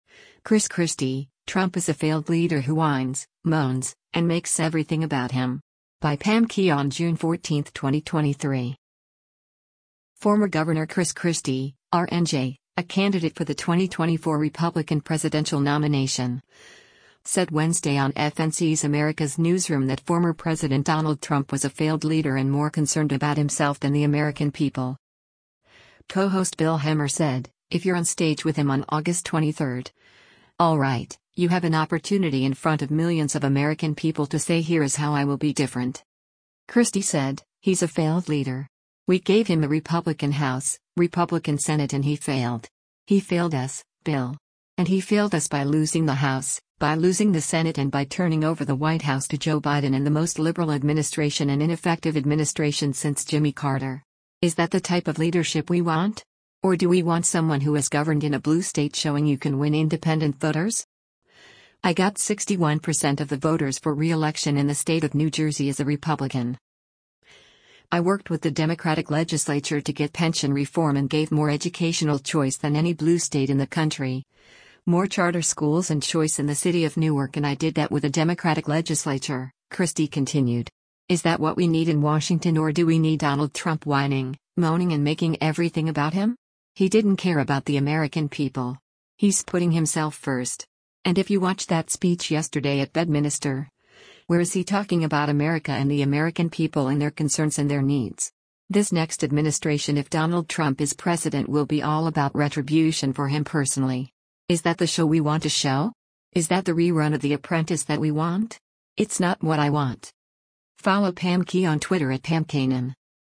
Former Gov. Chris Christie (R-NJ), a candidate for the 2024 Republican presidential nomination, said Wednesday on FNC’s “America’s Newsroom” that former President Donald Trump was a “failed leader” and more concerned about himself than the American people.